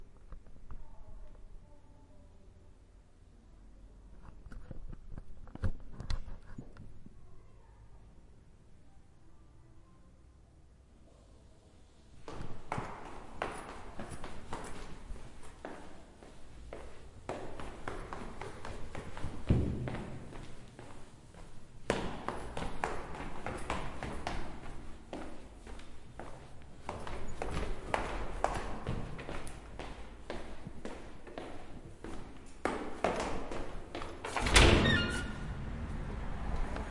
行走的声音 " 脚步声
描述：走在木地板上的脚
标签： 地面 台阶
声道立体声